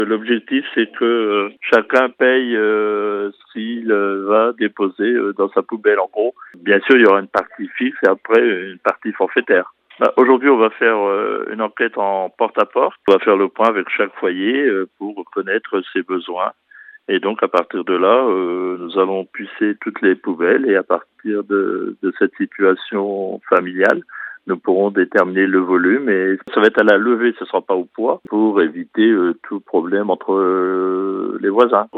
Elle sera juste différente et parfois plus intéressante selon les ménages .Son nom, un peu barbare, pourrait faire peur …la TEOMI ( Taxe d’Enlèvement des Ordures Ménagères Incitative ) fera son apparition sur le territoire de la CASPO en 2026 . LOGO HAUT PARLEURÉcoutez Joël DUQUENOY , Président de la communauté d’agglo – Il vous en explique le fonctionnement :